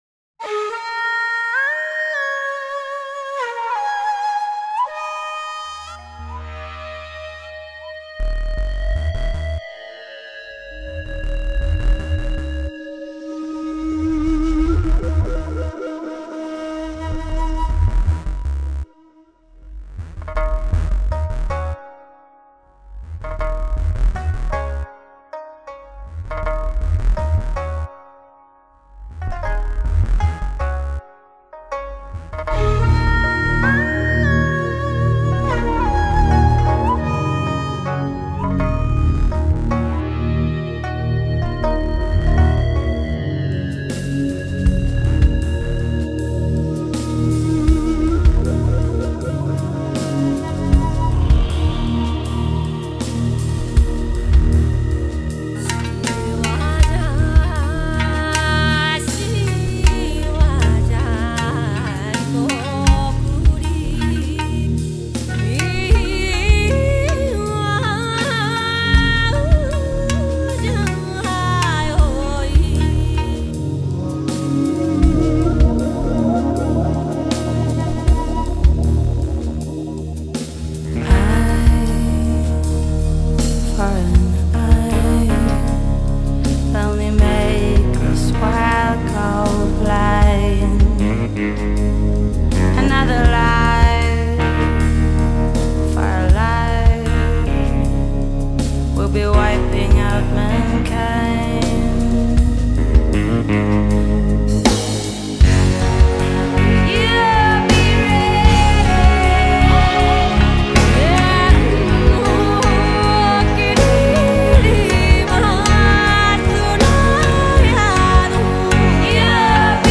原来扎根于世界音乐，巧妙融合现代电子音乐元素的风格，变成了现在激烈喧嚣的电子摇滚，只是偶尔能听到参杂其中的世界音乐元素。
以日本的独特五孔笛尺八加低音贝思开始
用不同语言合唱一首歌曲而且效果极为和谐，是这首歌最值得称道的地方。